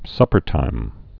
(sŭpər-tīm)